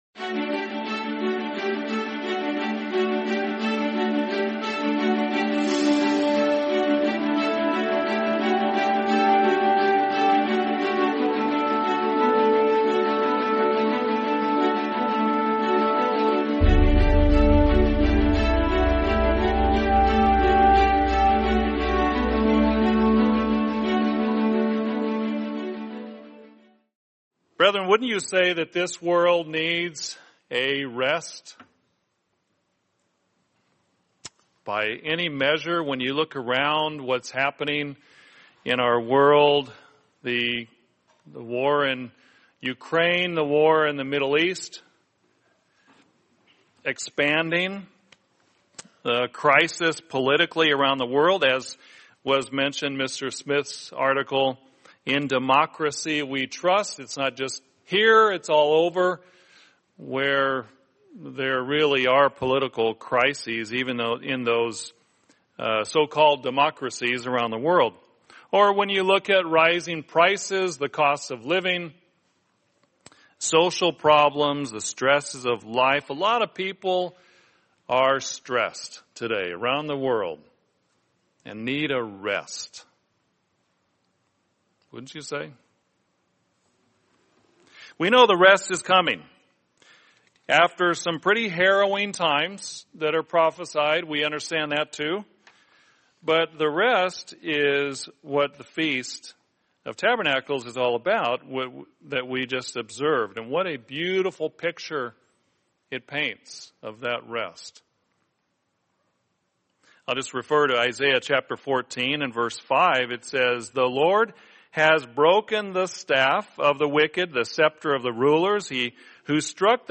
Sermon Rest in the Lord